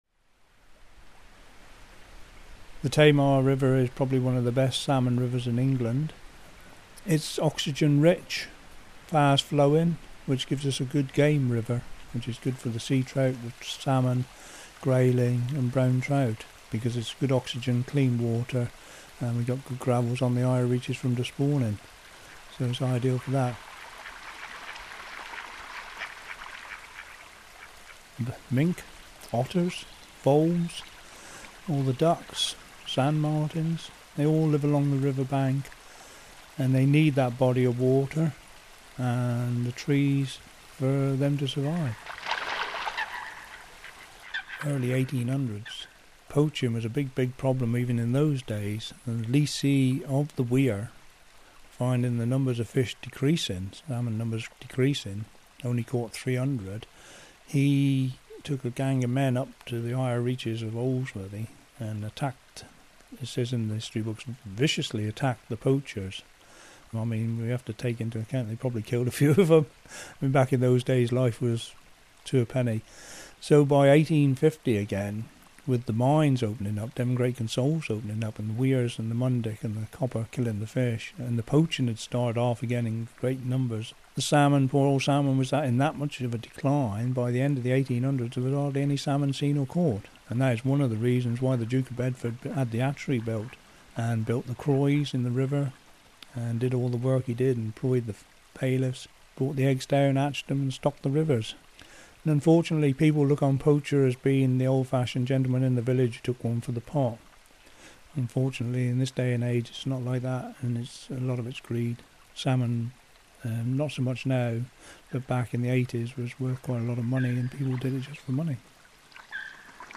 Listen to locals share memories from the past.